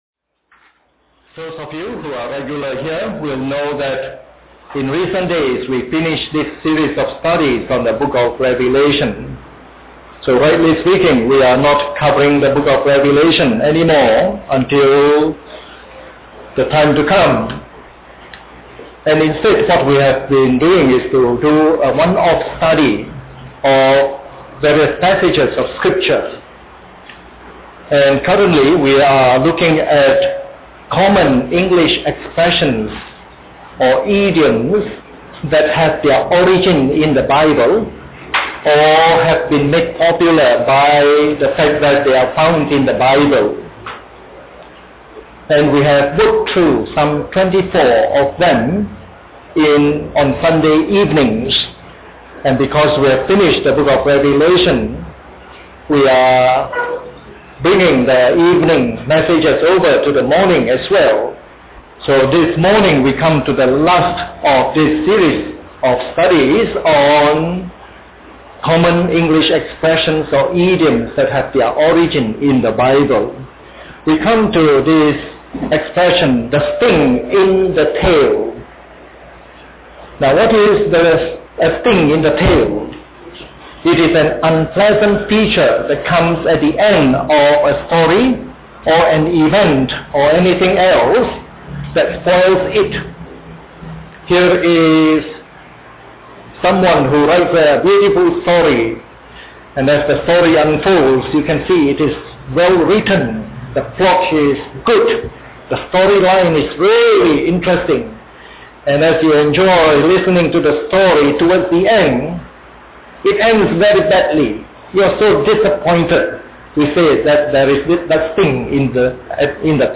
Preached on the 21st of December 2008.